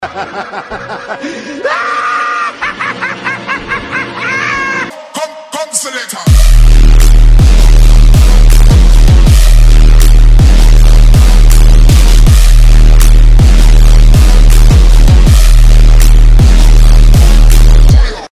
Intro BASS